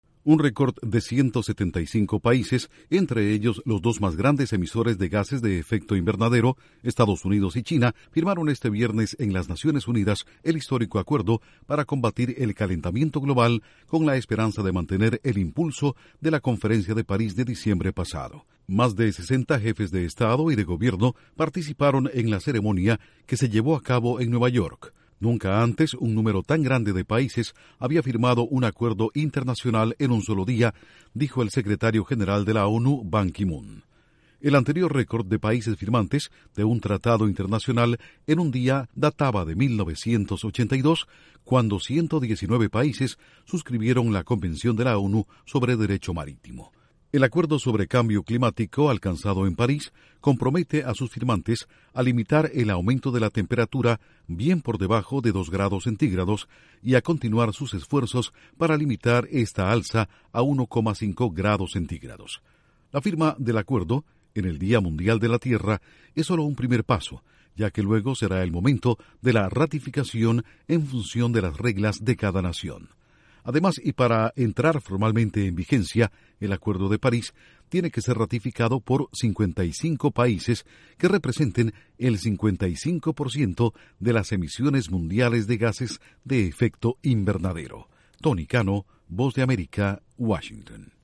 175 naciones firman en las Naciones Unidas, en Nueva York, un histórico acuerdo para combatir el calentamiento global. Informa desde la Voz de América